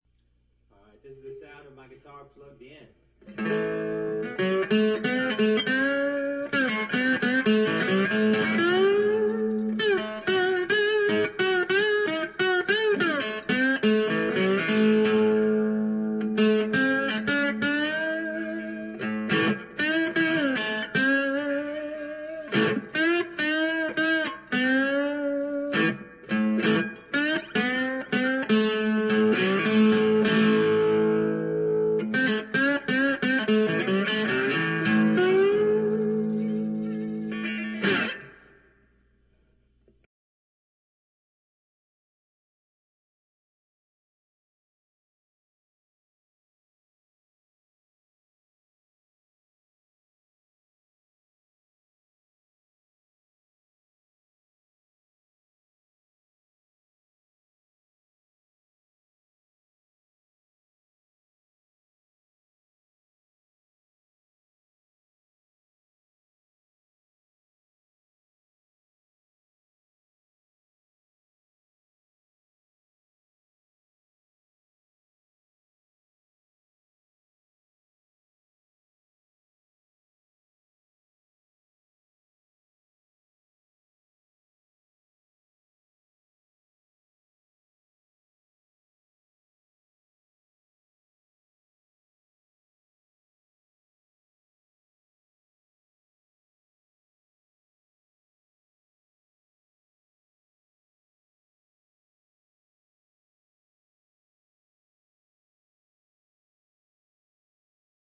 Through a small combo amp & then mic'd.